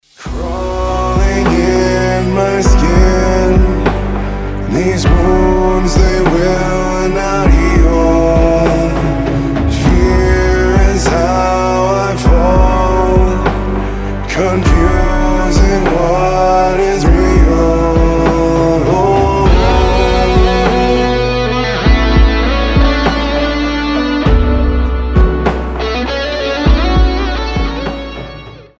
Кавер без надрыва